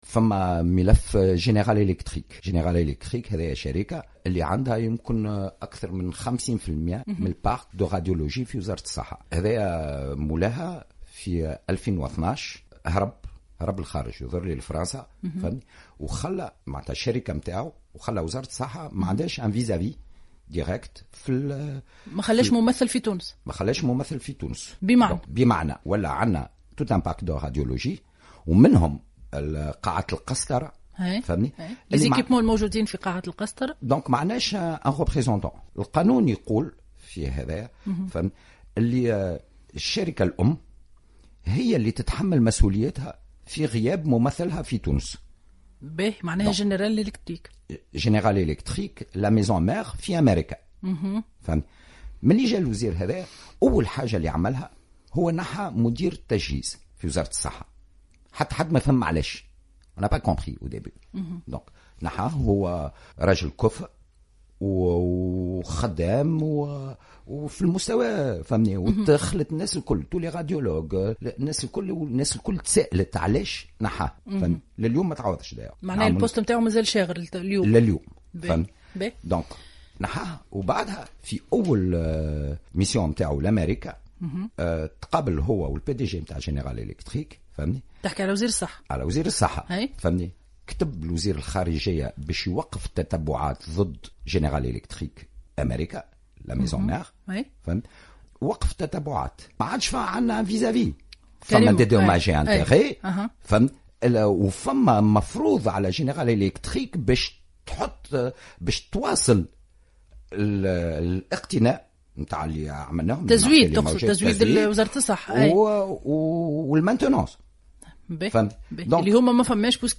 في مداخلة له في برنامج الحدث